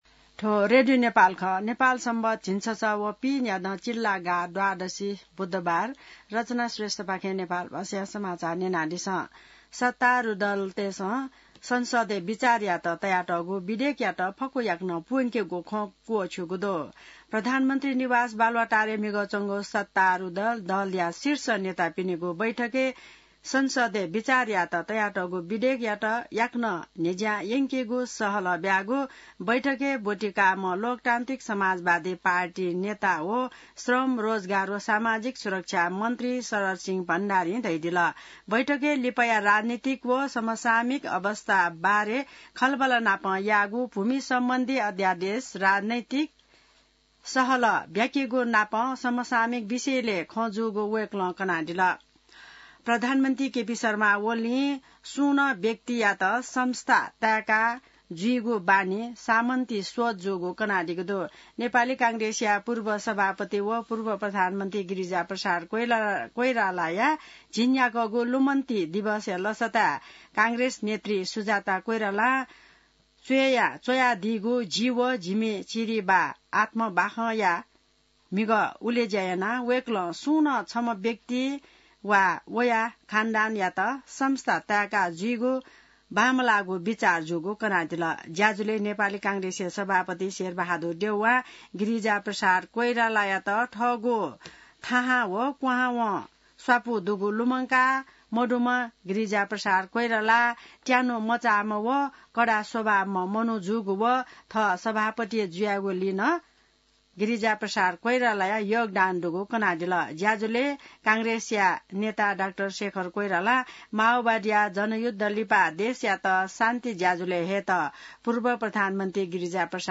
नेपाल भाषामा समाचार : १३ चैत , २०८१